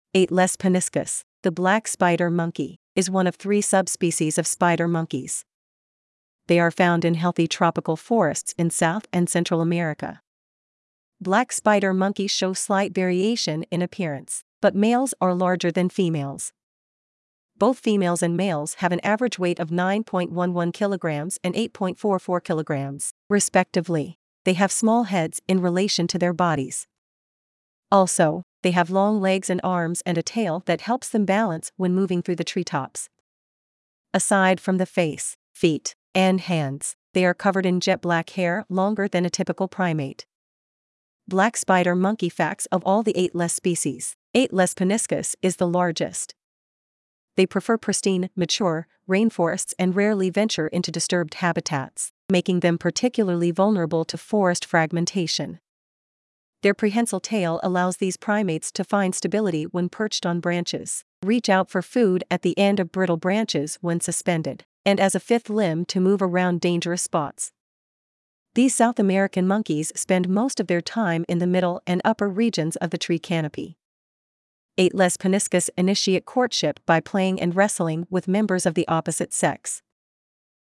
Black Spider Monkey
Black-spider-monkey.mp3